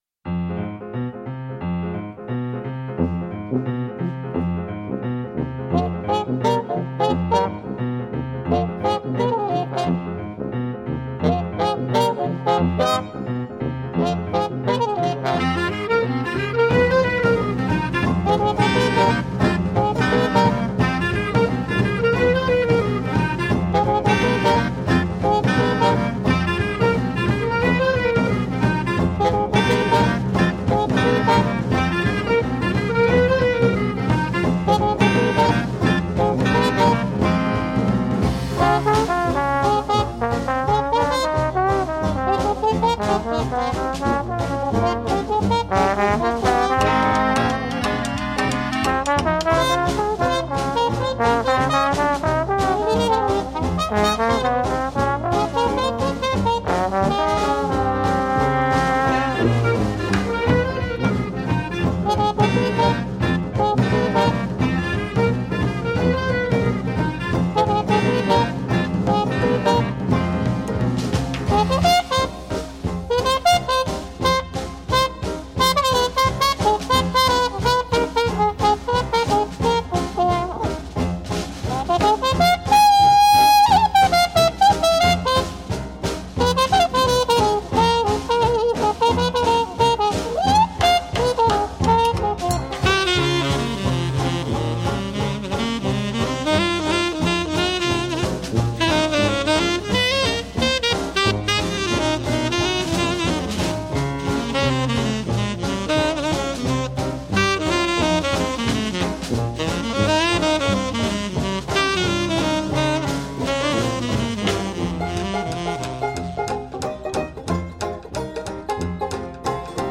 trompette
trombone
clarinette saxophones
piano
banjo
tuba
batterie